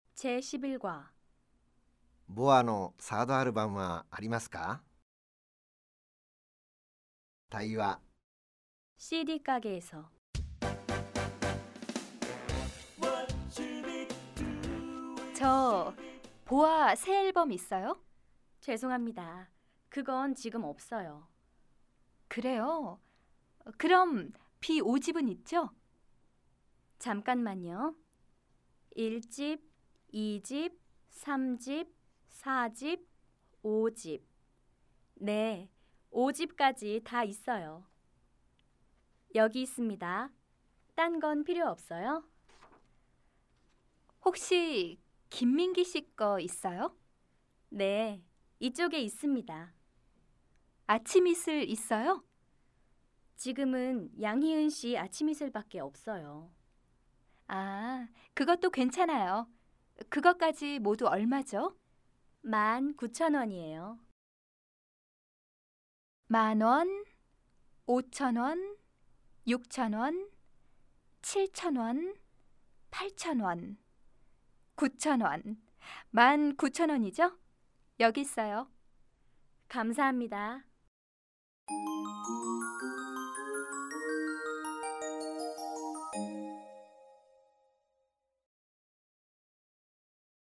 CDは声優さんたちによるソウル現地録音を敢行．